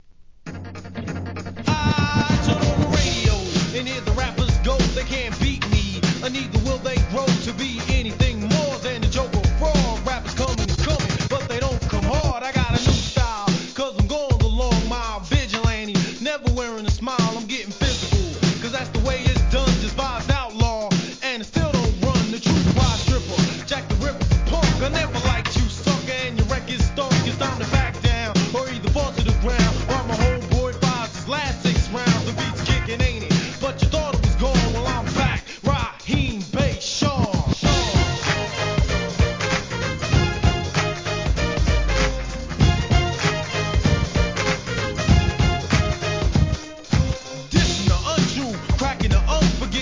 HIP HOP/R&B
ジャケもかっこいいですが音作りもセンス感じる1988年 HIP HOP!!